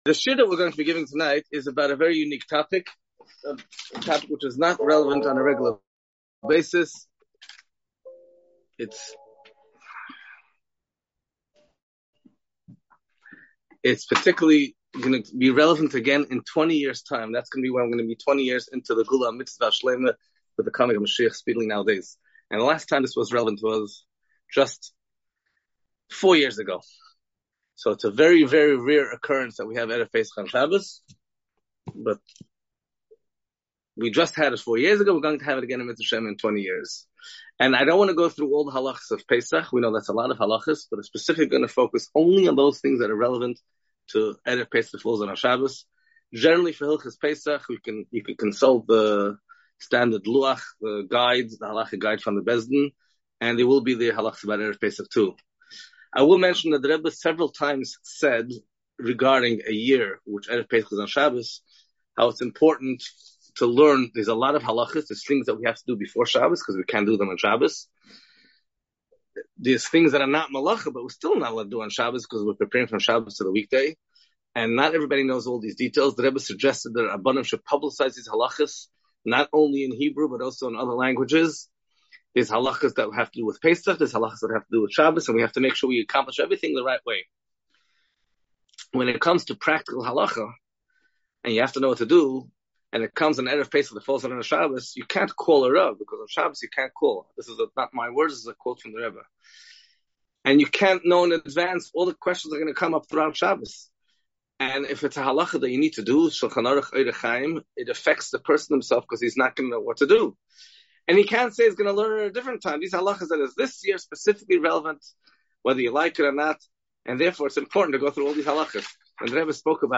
Shiurim (Classes)